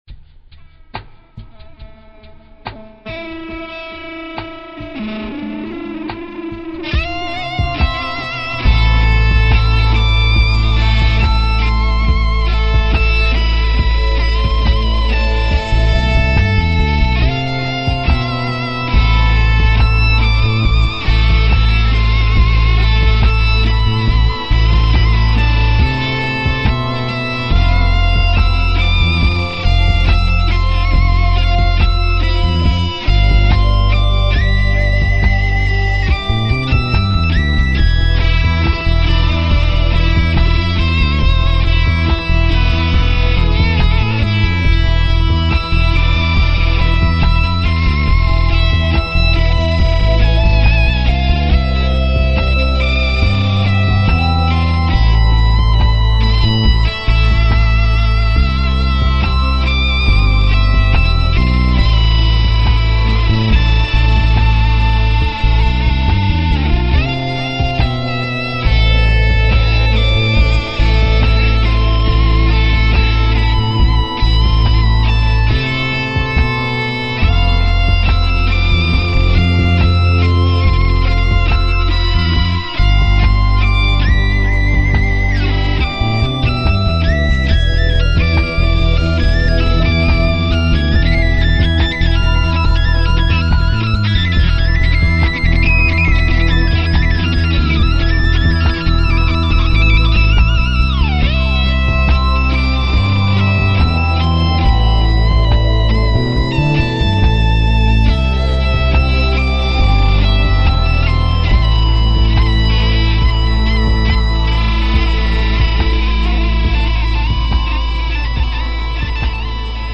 [Ambiance]